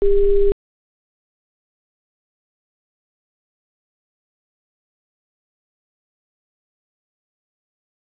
callwaiting_jp.wav